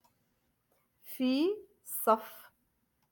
Moroccan Dialect-Rotation five-Lesson Sixty